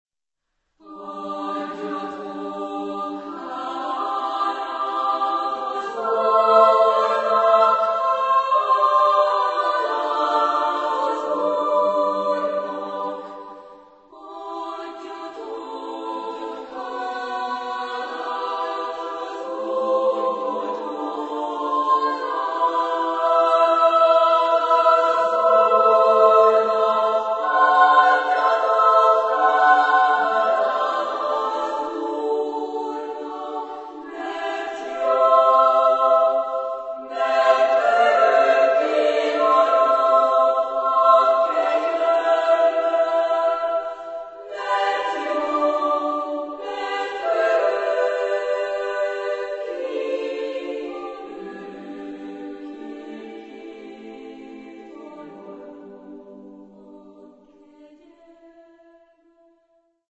Genre-Style-Forme : Sacré ; Chœur ; Prière
Type de choeur : SSATBB  (6 voix mixtes )
Tonalité : tonal avec variations modales